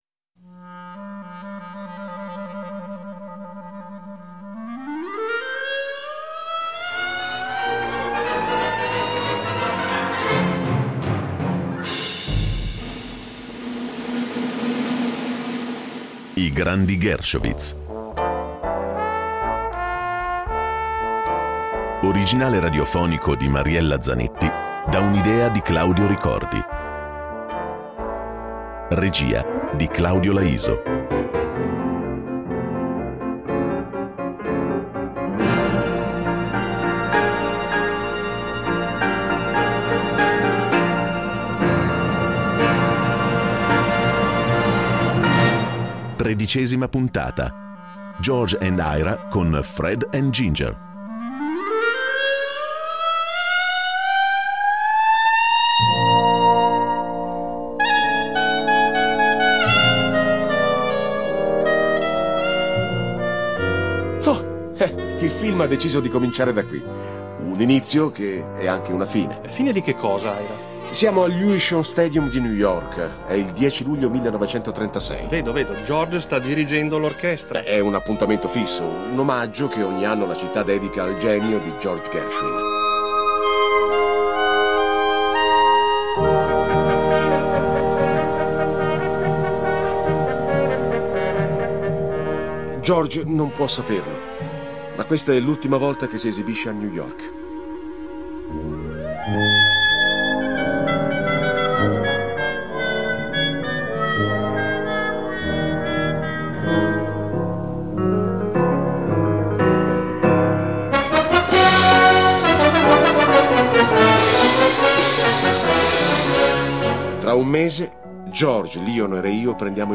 Rete Due propone il riascolto di un radiodramma dedicato allo straordinario rapporto tra Ira e George Gershowitz.